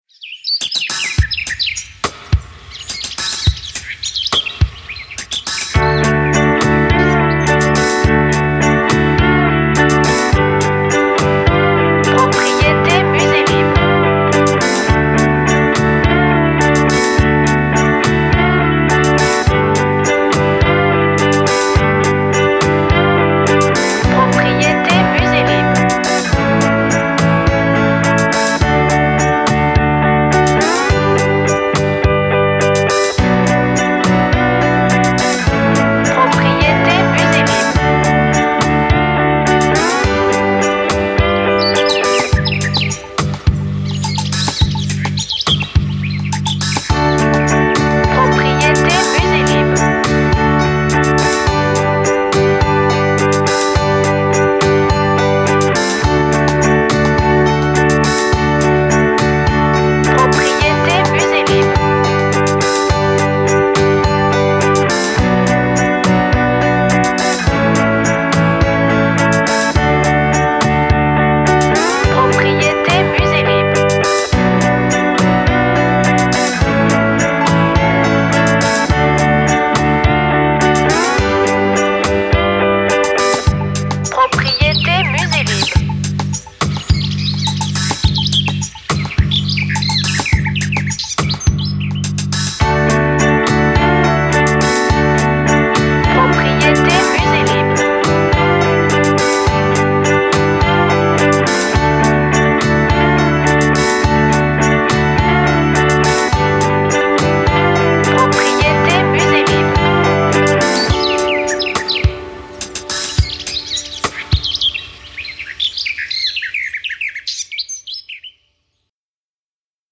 BPM Slow